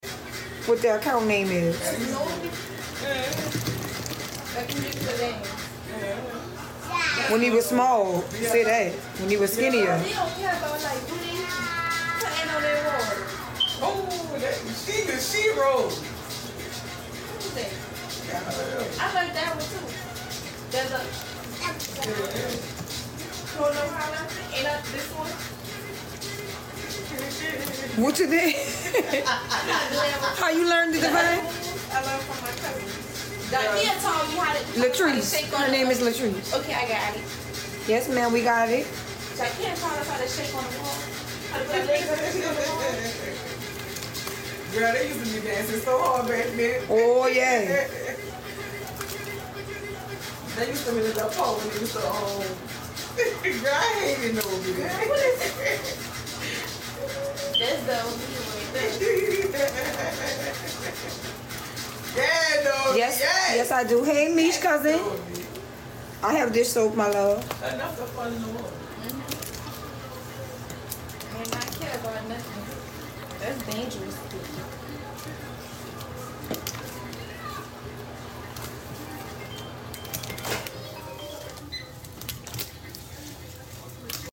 Powder Bomb And Handmixing From Sound Effects Free Download
Powder bomb and Handmixing from